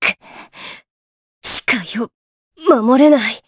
Aselica-Vox_Dead_jp.wav